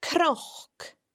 This sound can be heard in cnoc (a hill):